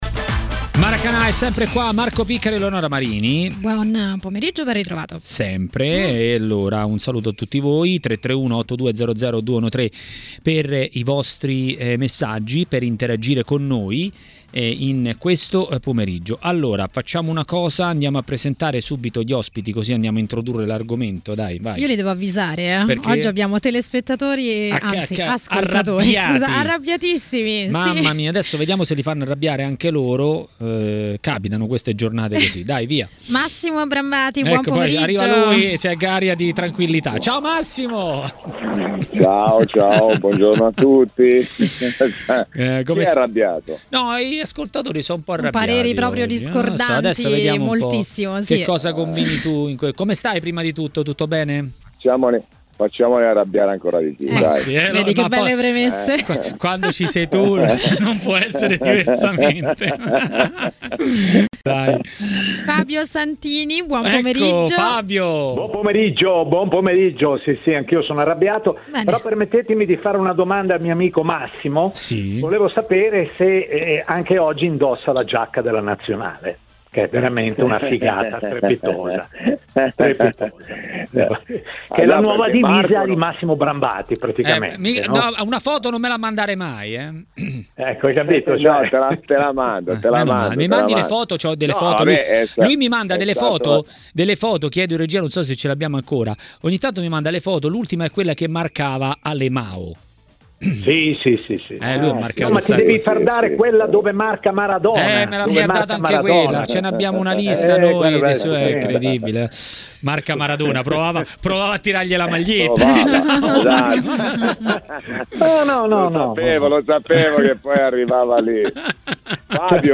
Per parlare di Inter a TMW Radio, durante Maracanà, è intervenuto l'ex calciatore Antonio Paganin.